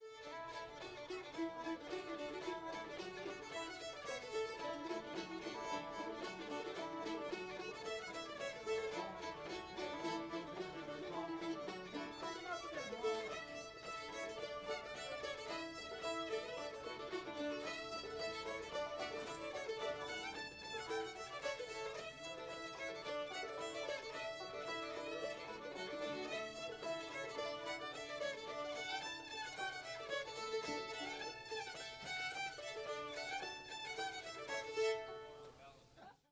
joke on the puppy [A]